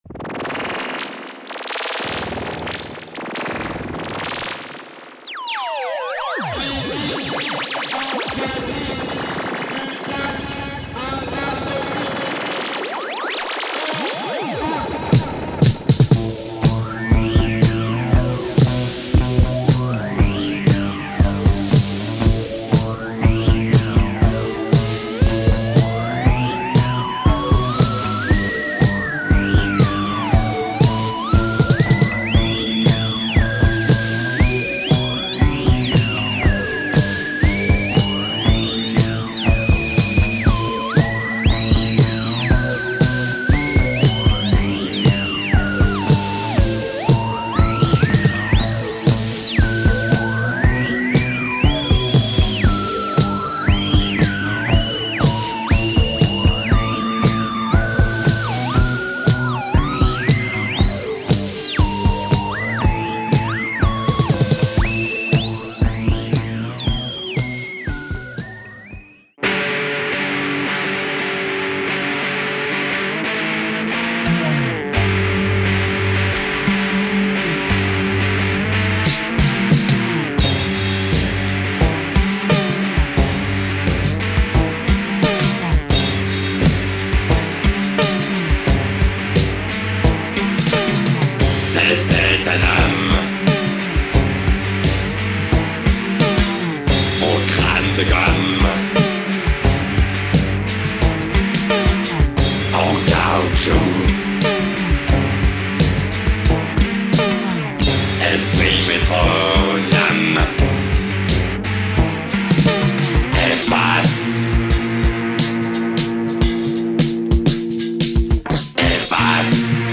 Cult French punk / wave / minimal electroid / weird project.